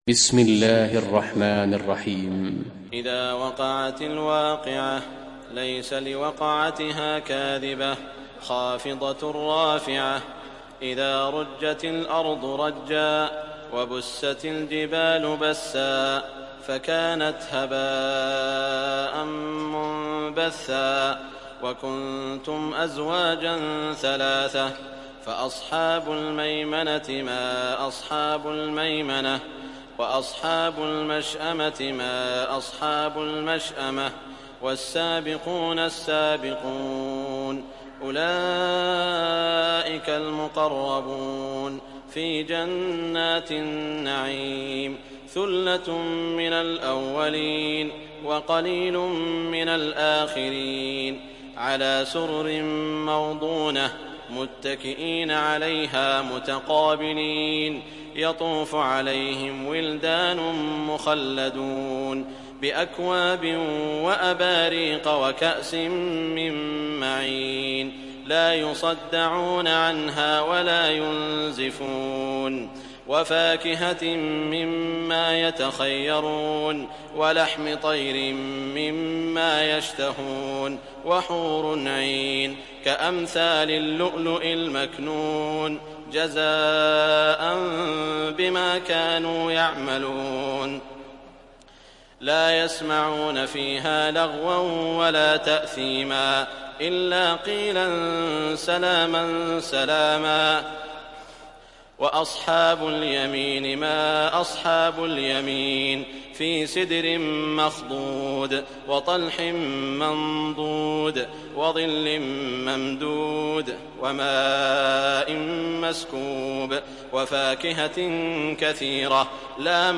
Sourate Al Waqiah Télécharger mp3 Saud Al Shuraim Riwayat Hafs an Assim, Téléchargez le Coran et écoutez les liens directs complets mp3